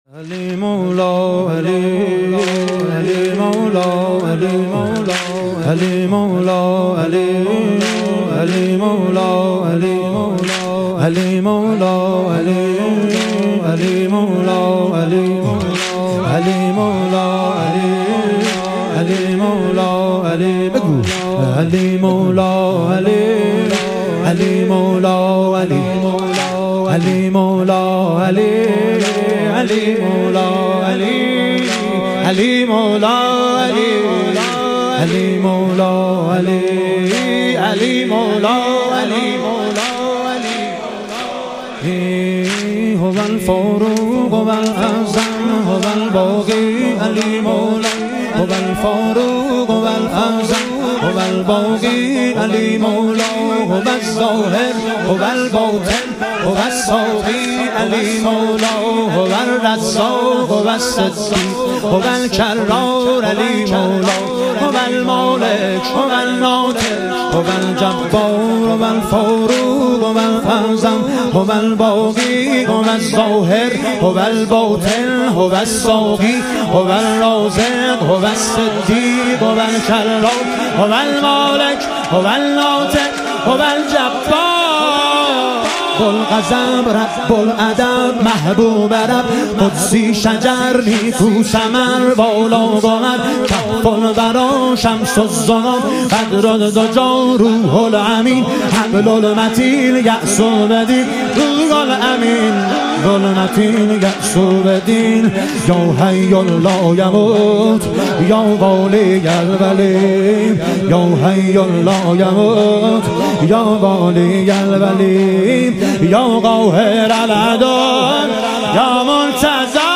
مدح امیرالمومنین حضرت علی (ع)